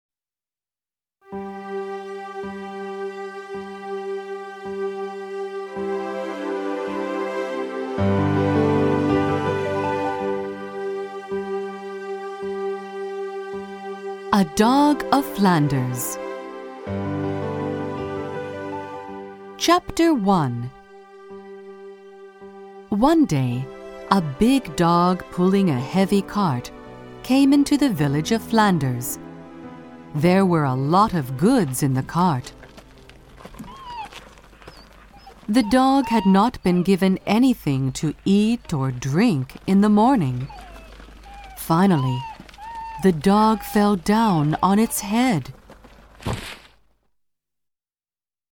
音声には効果音も取り入れていますので、学習者が興味を失わずに最後まで聴き続けることができます。